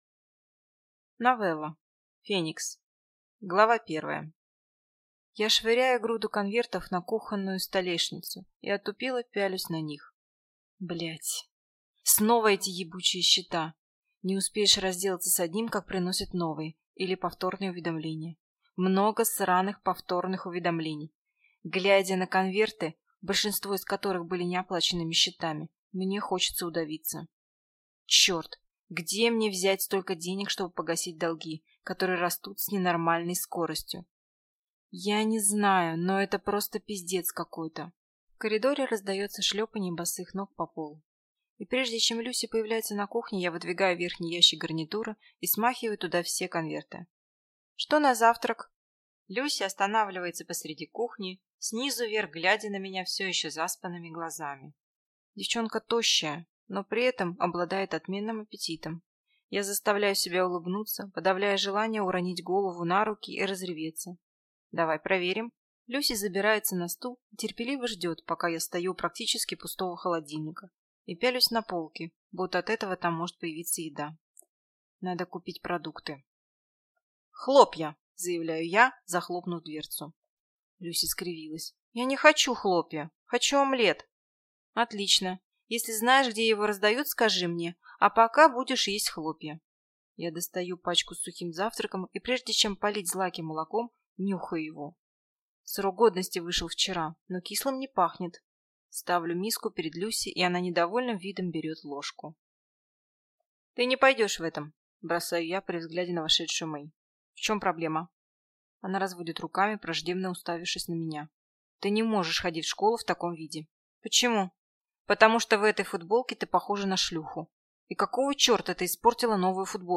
Аудиокнига Феникс | Библиотека аудиокниг